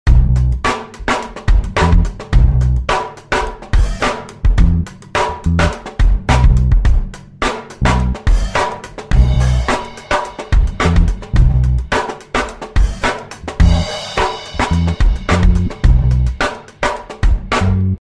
标签： 贝斯 舞蹈 电子 hip_hop 循环 男声 合成器
声道立体声